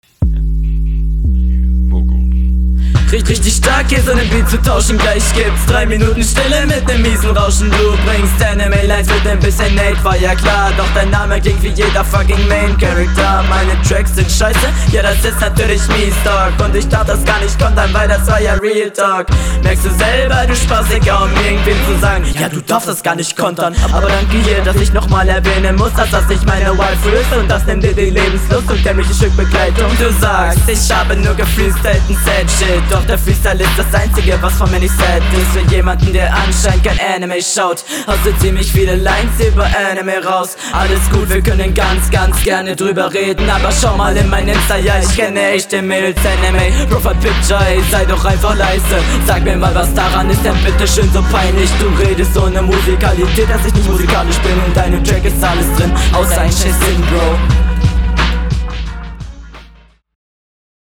Einstieg kommt etwas plötzlich beim ersten Mal Hören.